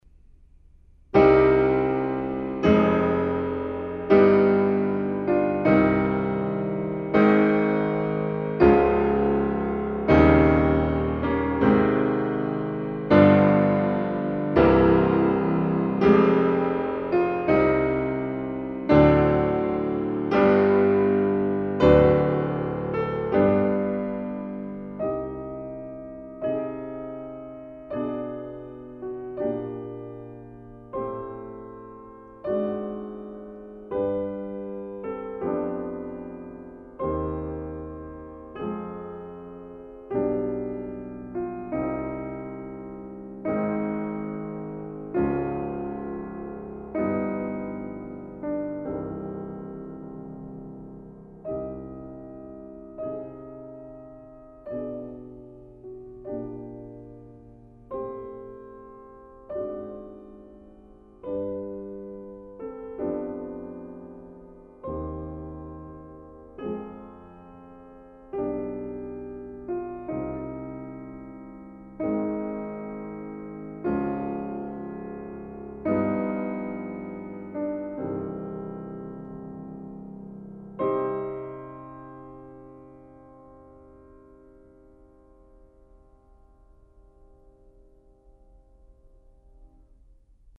20. Largo - C minor